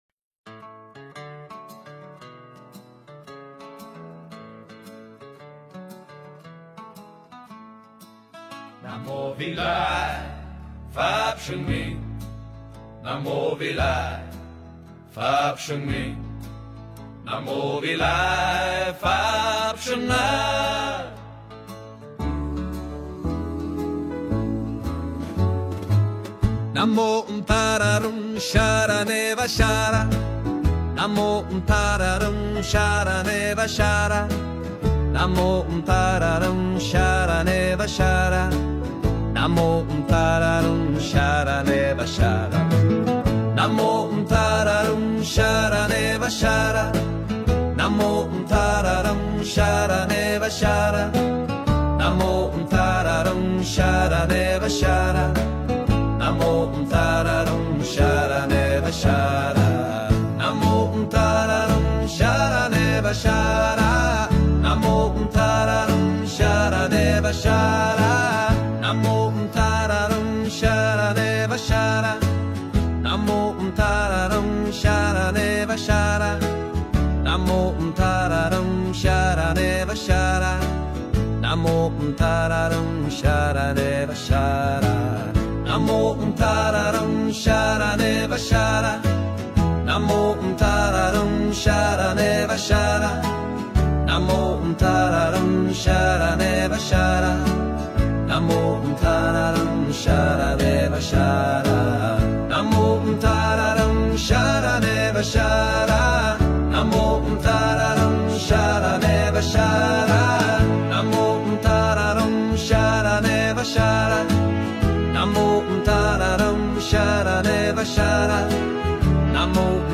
492. Hát Kinh Chú Chuyển Nghiệp, Chú Trừ Tà - Vị Lai Pháp